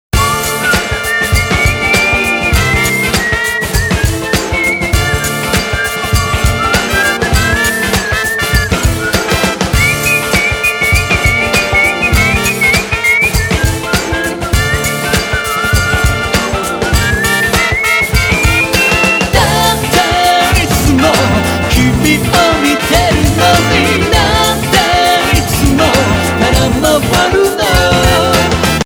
ソロの口笛とブラスのかけあい
ドラムスとギターの隙間にエレピを入れた。
ボーカルとトランペットに８分のディレイをつけた。
口笛には少し多めにつけた。